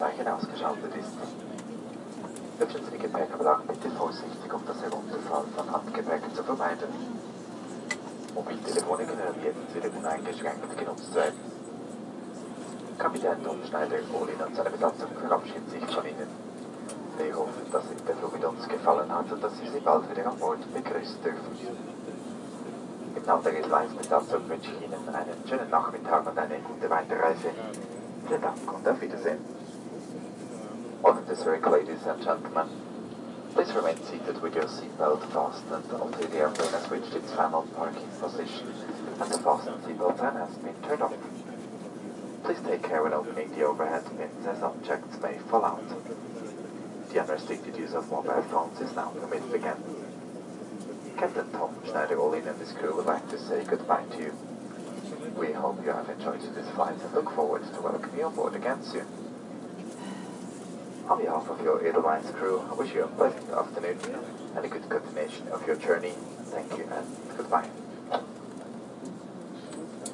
描述：飞机公告试点公告SGN越南至ZRH Edelweiss Air WK 61英国德语配音